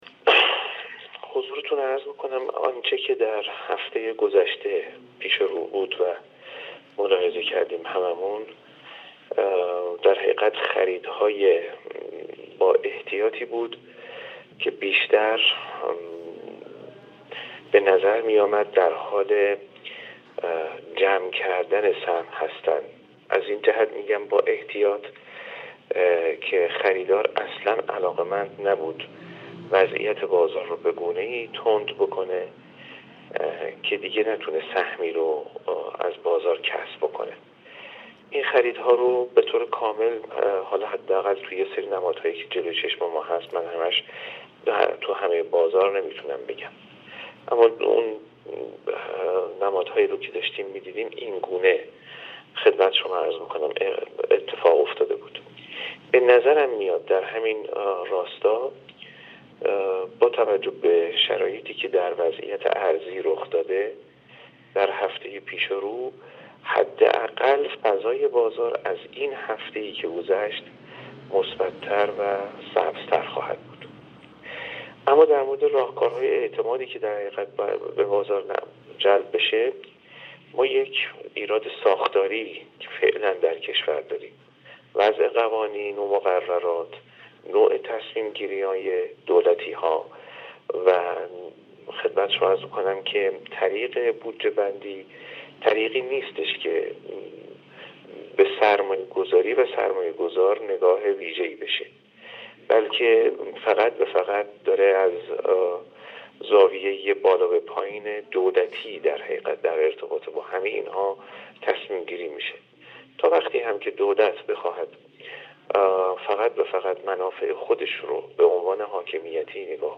مشروح این مصاحبه را اینجا بخوانید